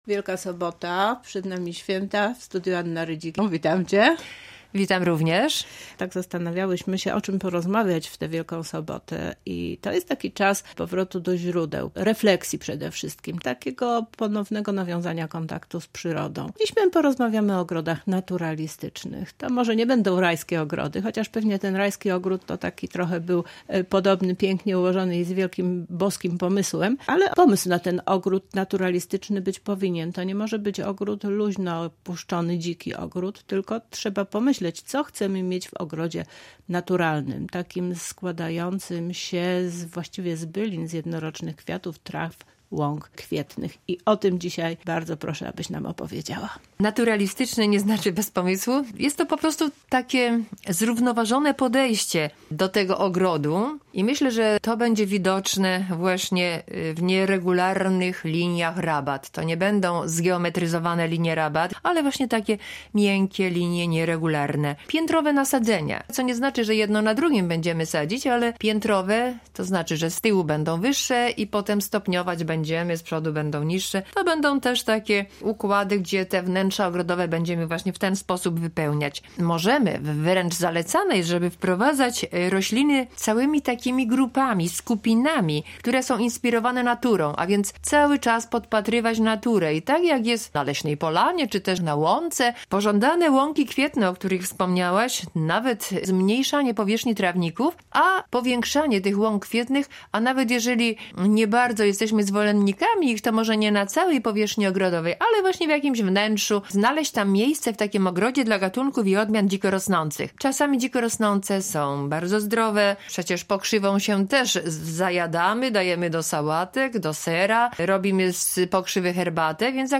Więcej szczegółów w rozmowie